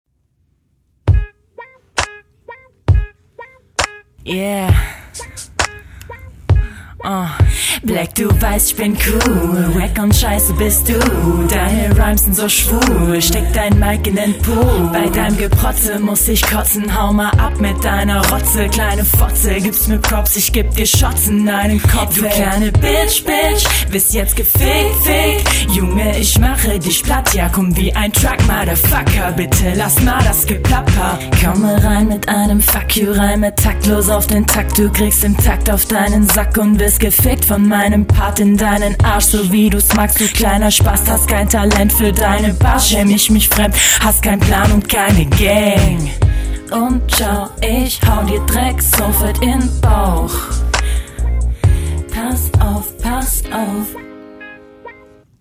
Schön auf den beat geflowt, Gesang ist wieder cool, finde die runde sehr gut gelungen! …
In ner Battlerunde singen ist mal was neues und experimentvoll, das klingt echt schön, sehr …